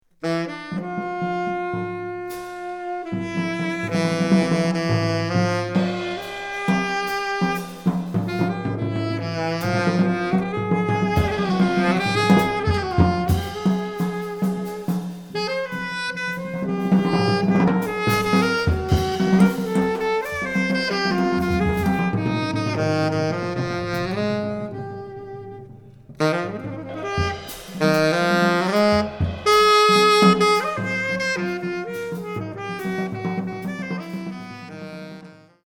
saxophonist
drummer